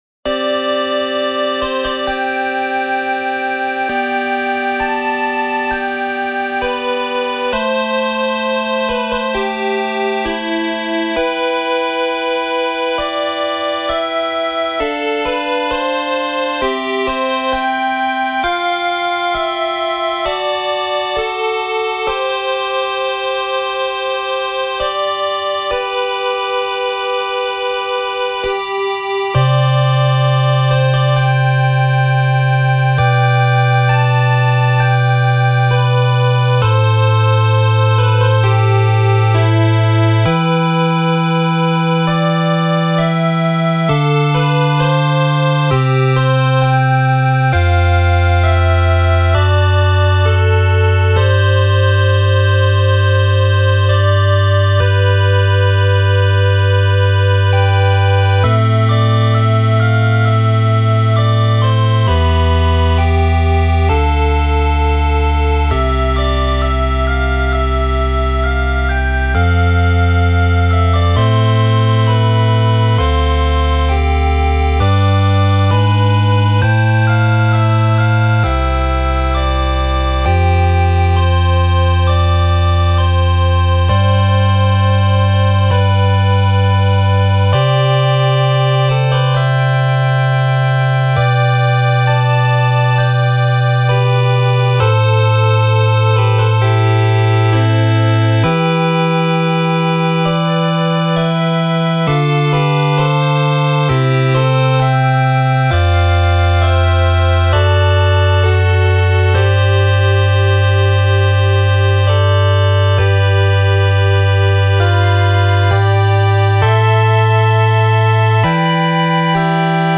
ジャンルファミコン風、8-Bit
使用例城、王宮、教会、祠
BPM６６
使用楽器8-Bit音源
原曲はお城や王宮で流れる楽曲でしたが、こちらは完全に教会や祠のイメージとなりました。
8-Bit音源のため仕方のないことですが、吹奏楽とは名ばかりに…。